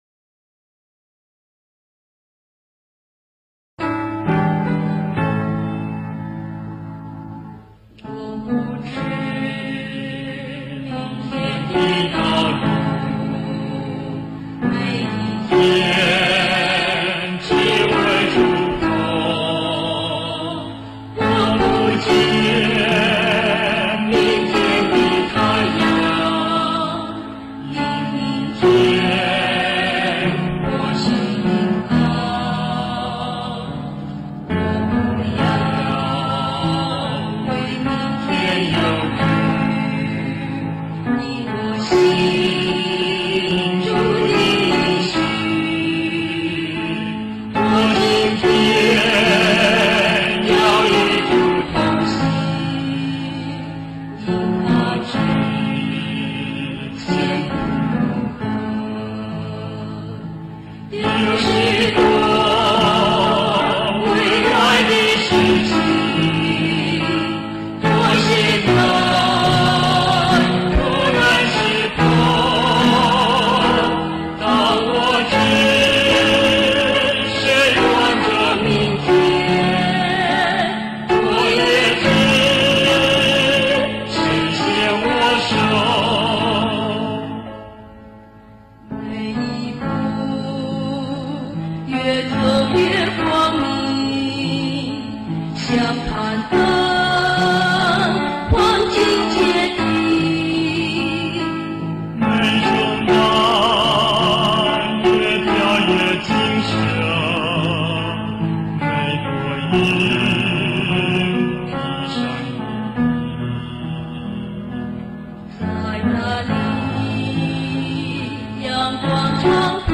情緒飽滿動人
副歌突然升高，全曲的主題呈現出喜樂的高音：
整首詩歌節奏完全一致，非常口語化。